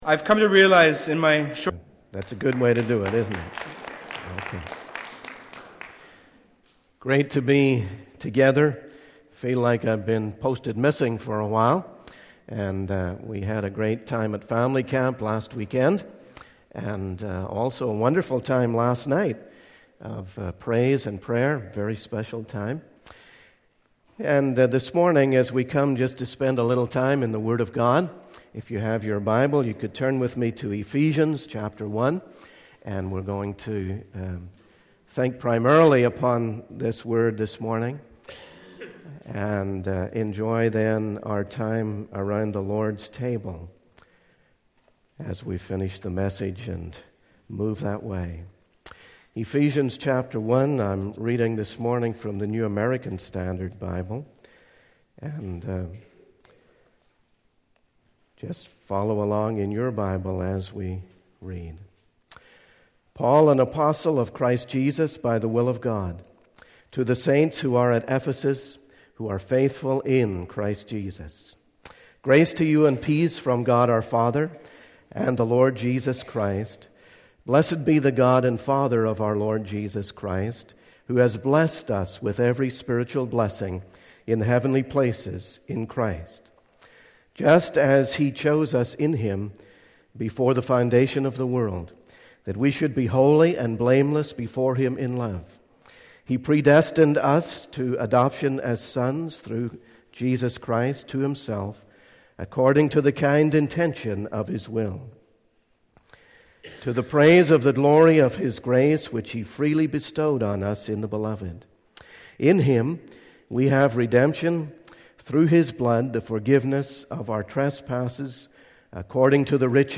The sermon transcript is from a sermon on the book of Ephesians, specifically chapters 4, 5, and 6. The speaker emphasizes the importance of understanding our walk in Christ and highlights the repeated mentions of being in Christ and in accordance with His will in the chapter.